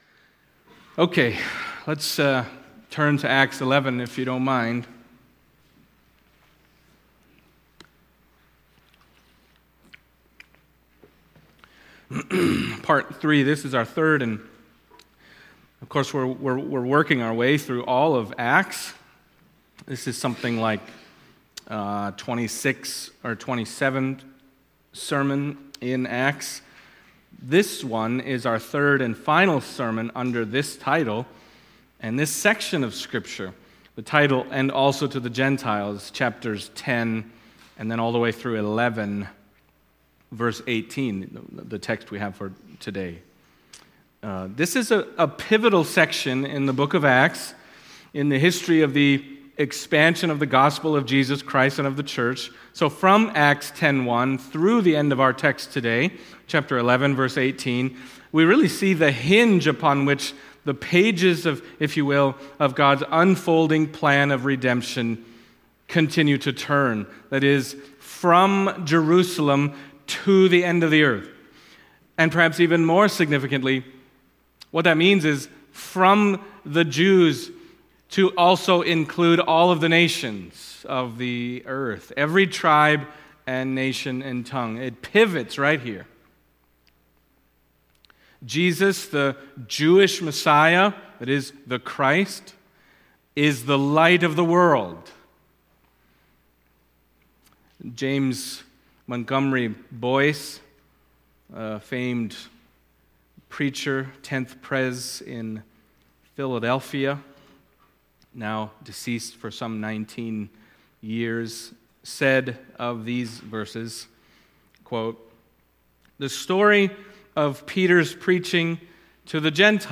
Acts Passage: Acts 11:1-18 Service Type: Sunday Morning Acts 11:1-18 « And Also to the Gentiles